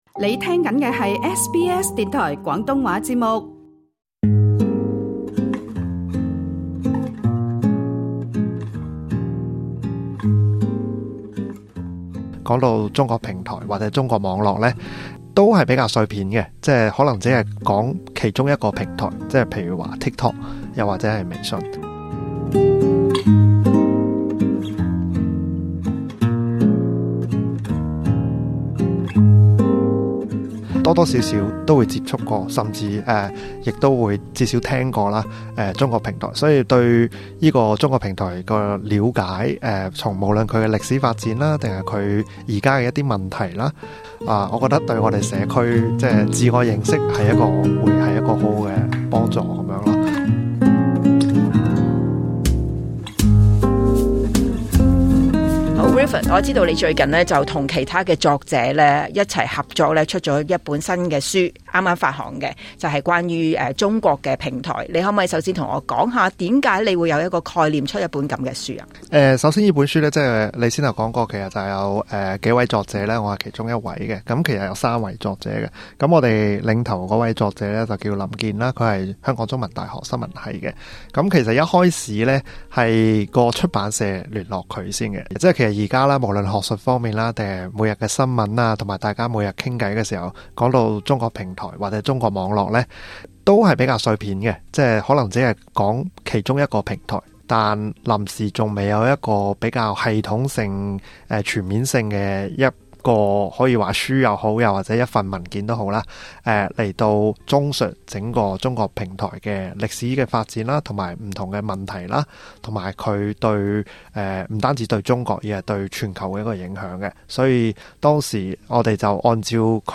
接受SBS廣東話訪問時分析，中國數碼平台向全球發展大致分為四種模式，個人與社會對中國平台的認識，應該要統性地了解其歷史、發展與潛在風險。